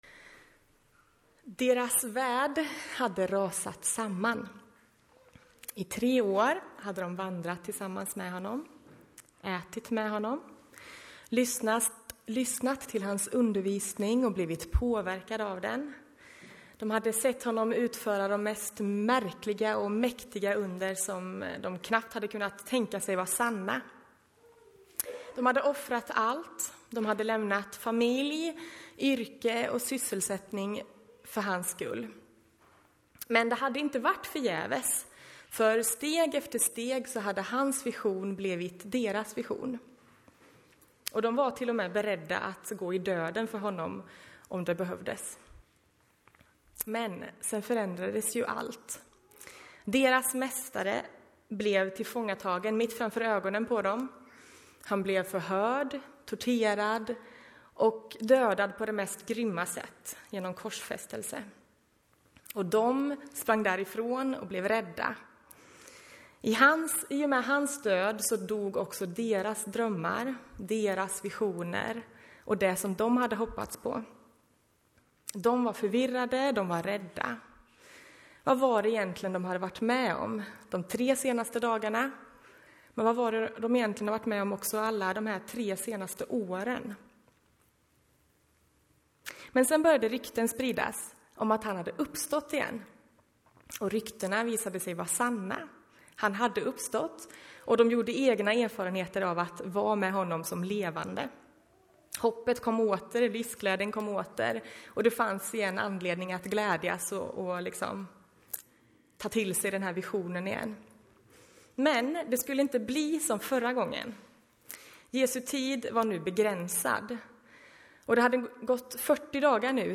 Predikoserie: Genom NT (våren 2018) Etiketterad med Anden , Apostlagärningarna , Pingst